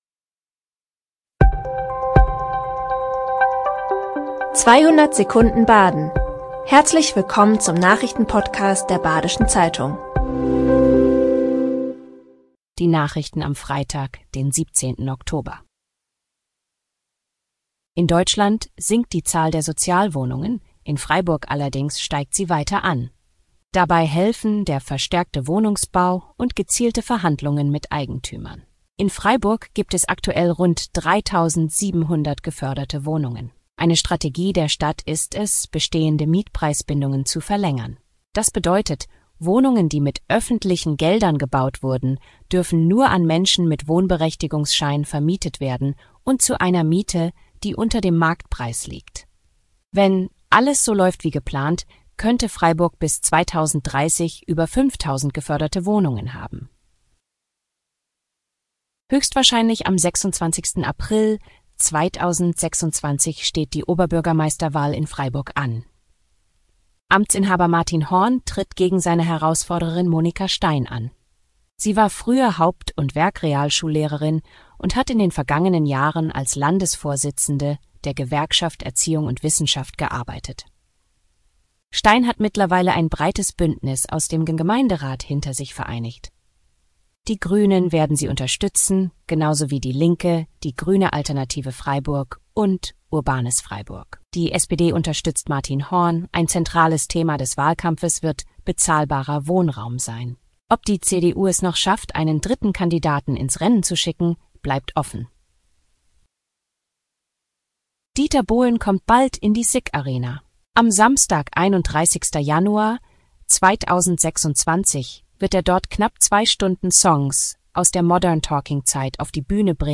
5 Nachrichten in 200 Sekunden.
Nachrichten